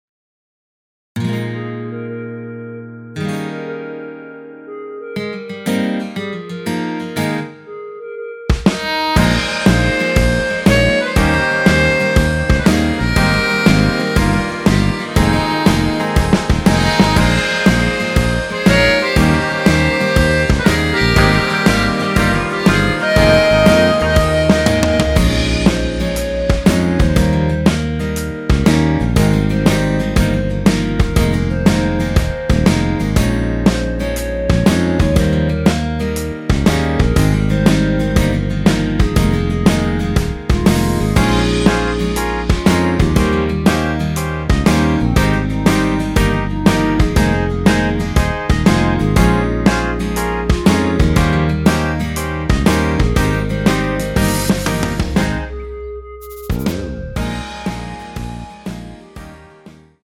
원키에서(-1)내린 멜로디 포함된 MR입니다.
Ab
앞부분30초, 뒷부분30초씩 편집해서 올려 드리고 있습니다.
중간에 음이 끈어지고 다시 나오는 이유는